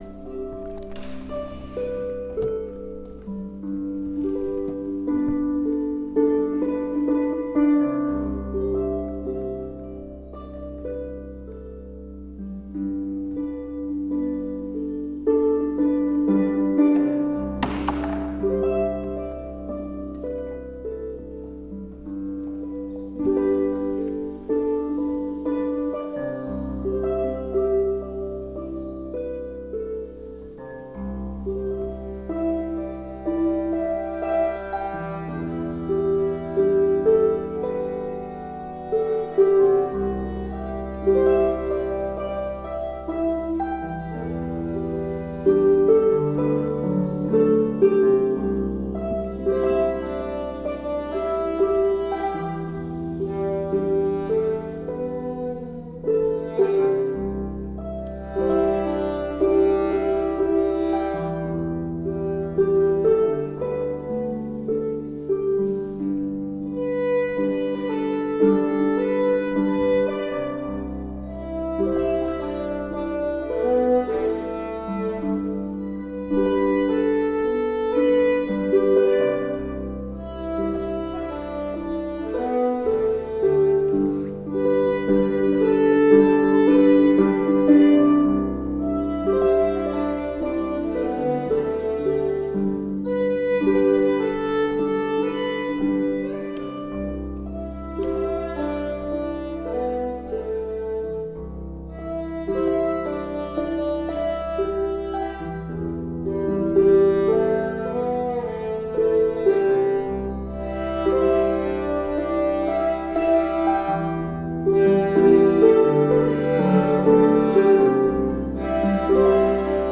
Workshop October 2025